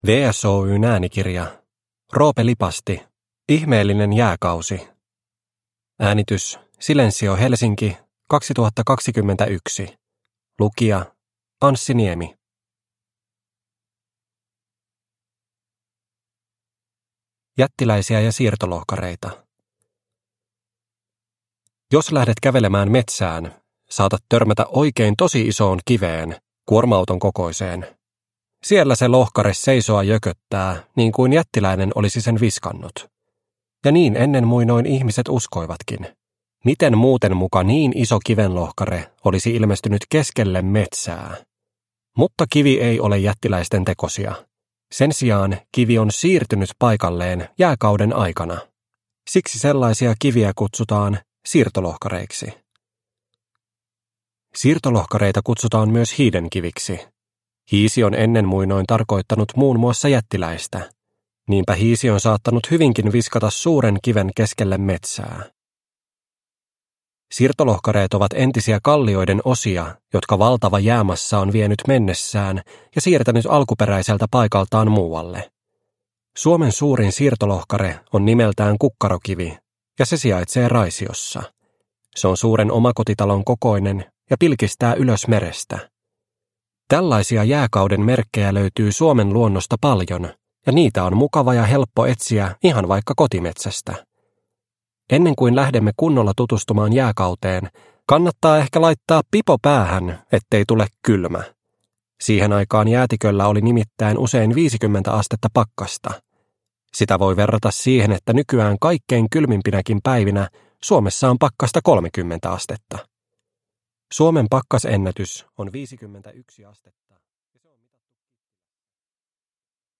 Tietopalat: Ihmeellinen jääkausi – Ljudbok